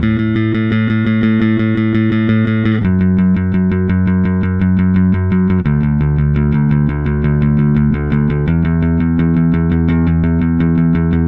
Indie Pop Bass 02.wav